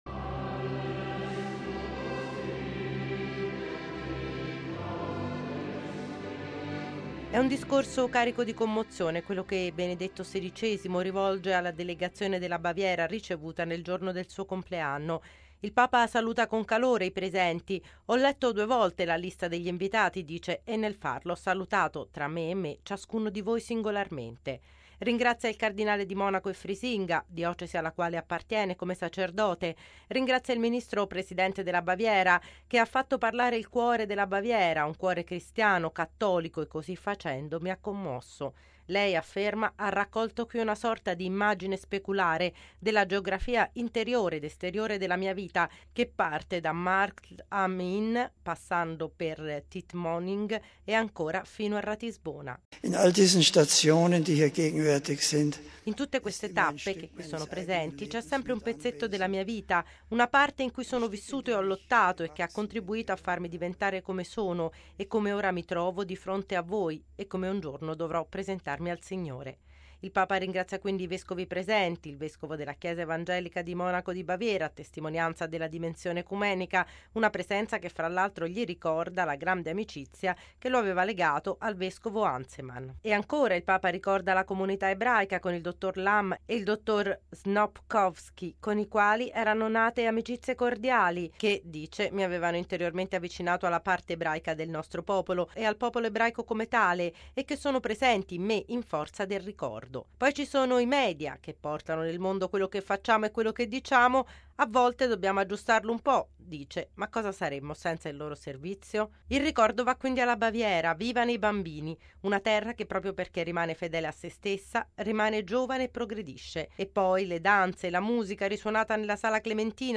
◊   I ricordi della sua Baviera, della sua vita, dell’amicizia con due ebrei che lo hanno avvicinato al popolo ebraico, sono stati rievocati stamani dal Papa nell’incontro con la delegazione della Baviera composta da circa 170 persone, nella Sala Clementina. Benedetto XVI ha ringraziato tutti, particolarmente il cardinale Reinhard Marx, arcivescovo di Monaco e Frisinga, e il ministro presidente della Baviera, Horst Seehofer.